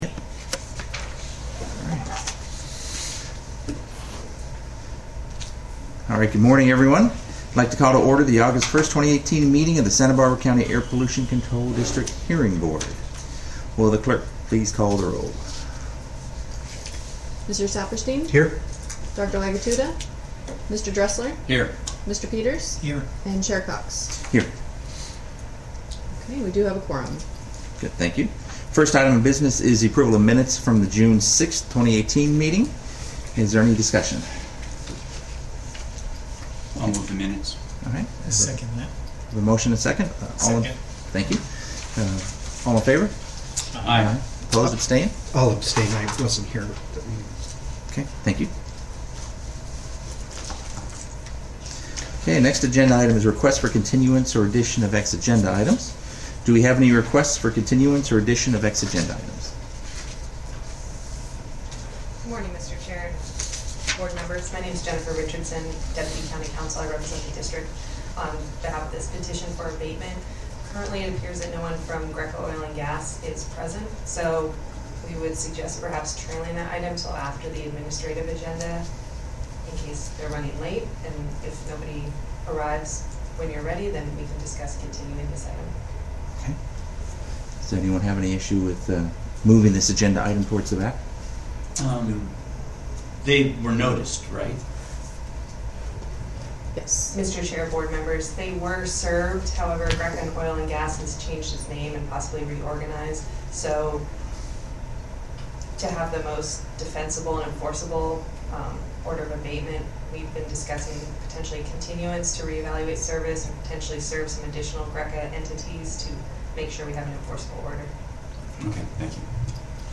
Agenda | Public Notice | Meeting Audio